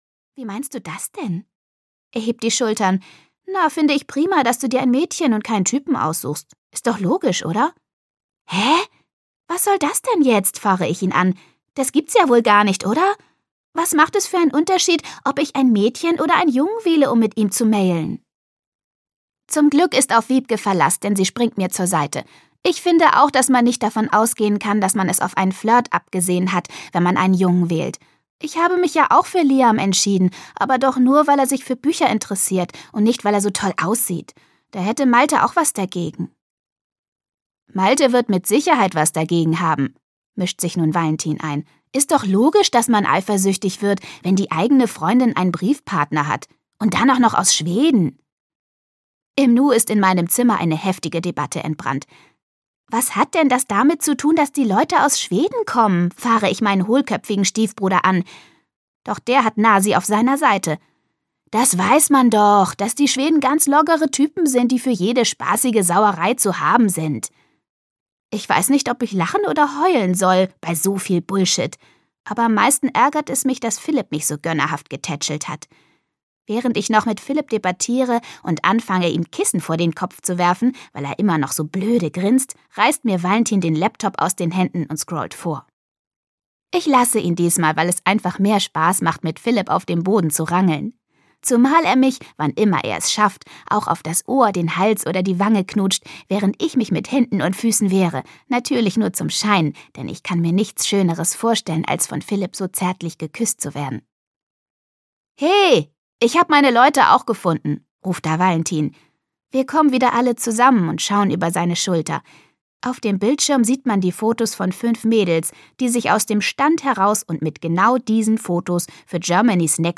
Freche Mädchen: Schwedenküsse sind die besten - Martina Sahler - Hörbuch